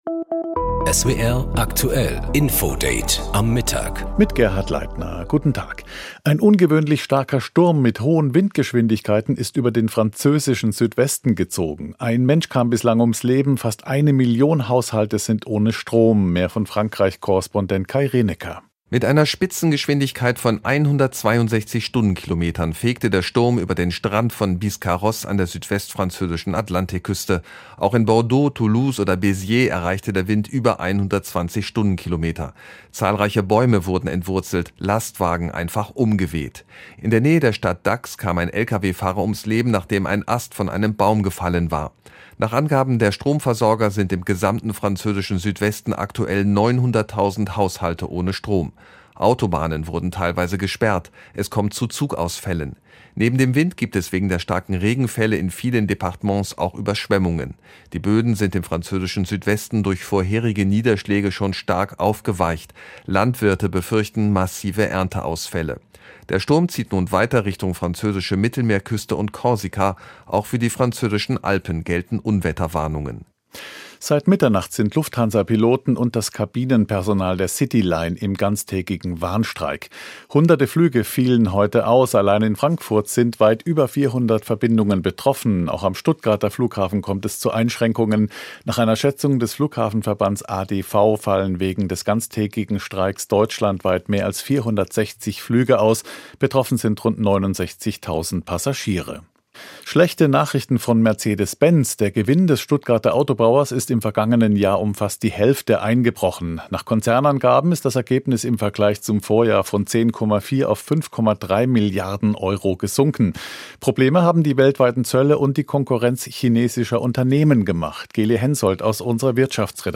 Nachrichten